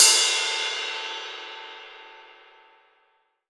Index of /90_sSampleCDs/AKAI S6000 CD-ROM - Volume 3/Ride_Cymbal1/20INCH_ZIL_RIDE